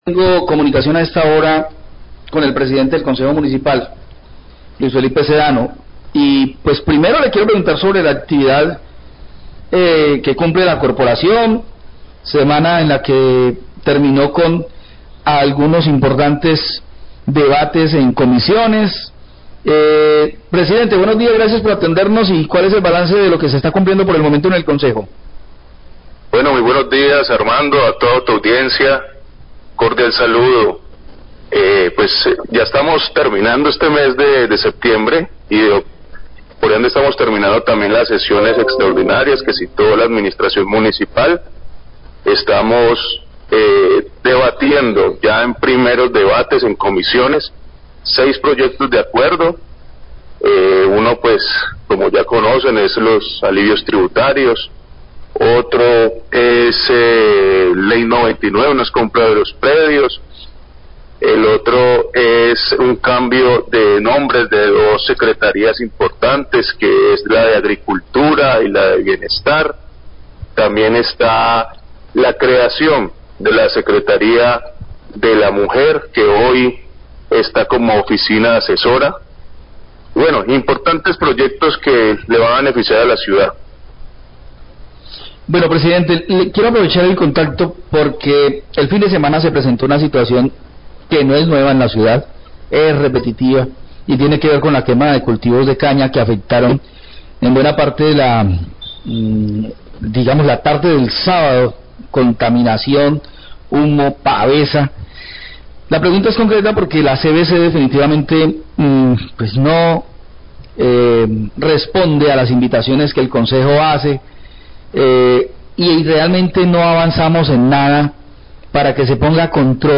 Breve entrevista con el Pdte de Concejo Municipal de Buga por monocultivos y malos olores, además de comentarios para CVC
Breve entrevista con el Pdte de Concejo Municipal, Luis Felipe Sedano, de Buga, por la quema de monocultivos y malos olores que se presentan en el municipio. Periodistas hacen comentarios por faltta de acción de CVC.